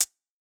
UHH_ElectroHatA_Hit-20.wav